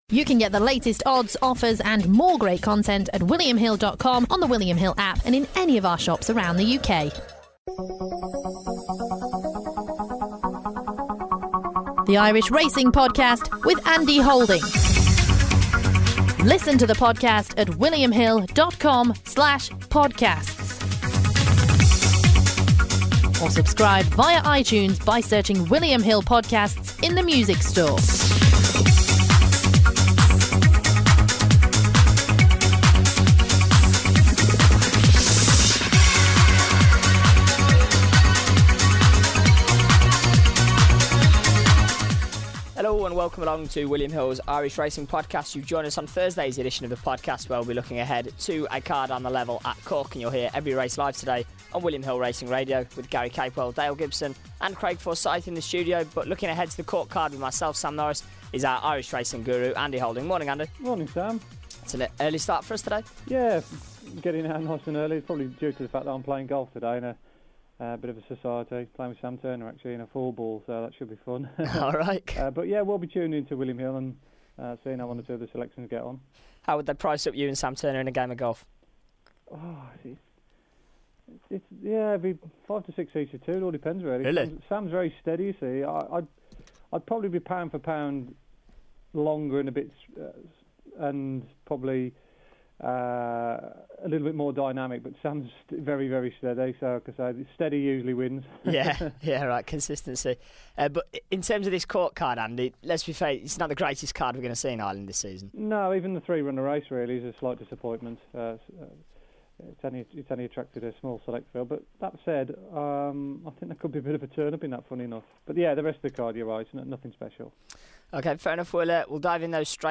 on the line to look ahead to every race on the day offering a selection in each contest.